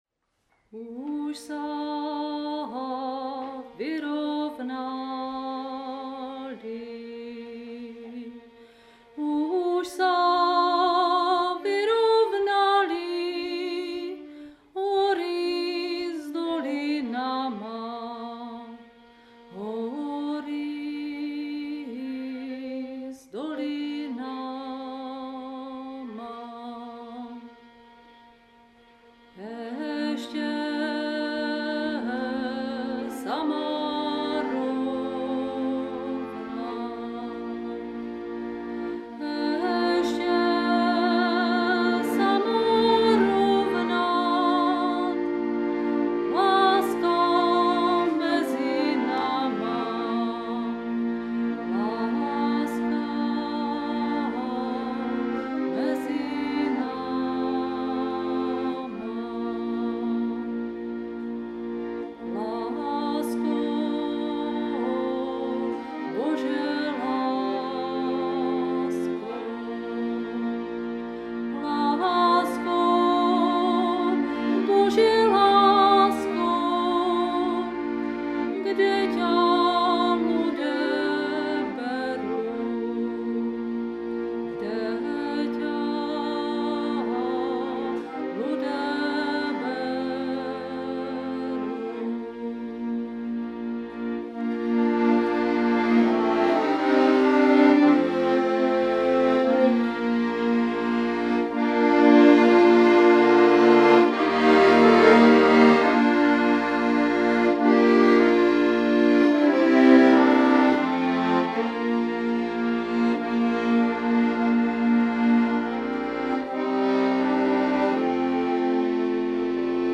❥ Ženský sboreček Nivnička ♡
cimbál a CM Mladí Burčáci